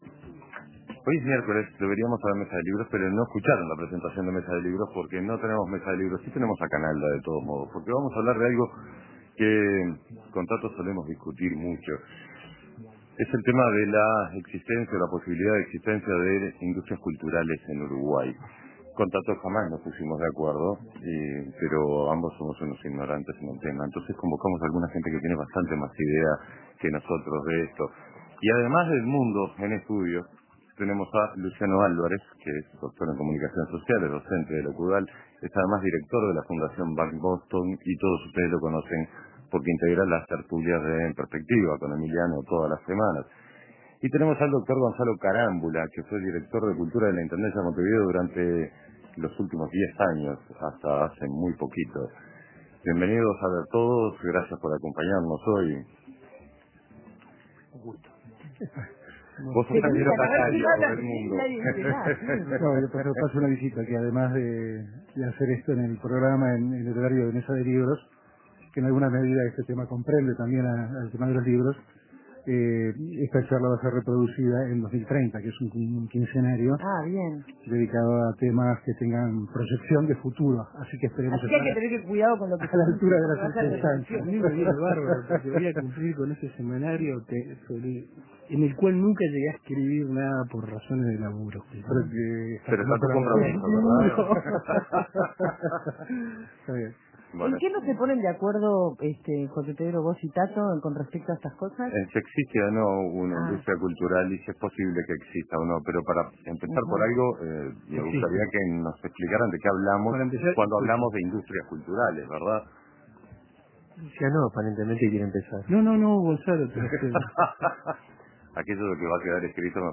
Las tres grandes áreas tradicionales son: lo audiovisual, lo editorial y la industria fonográfica. Son estructuras compuestas de pequeñas empresas, dijo uno de los entrevistados. El volumen de la actividad económica de este sector puede seguir creciendo, apuntó otro.